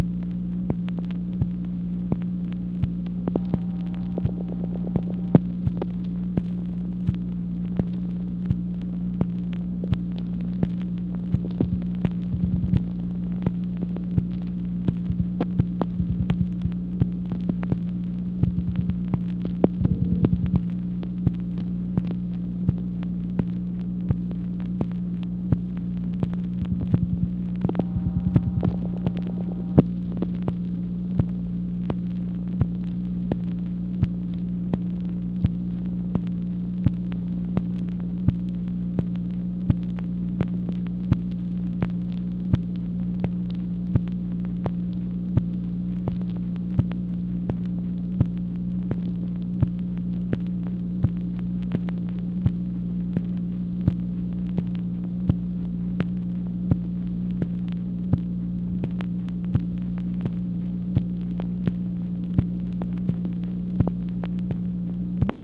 MACHINE NOISE, July 22, 1966
Secret White House Tapes | Lyndon B. Johnson Presidency